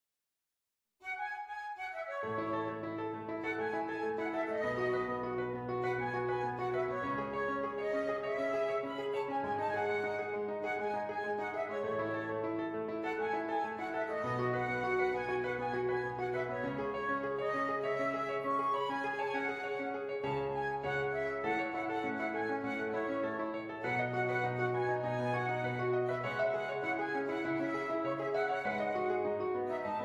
Flute Solo with Piano Accompaniment
Does Not Contain Lyrics
E Major
Moderate Pop